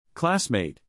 classmate / CLASS-mate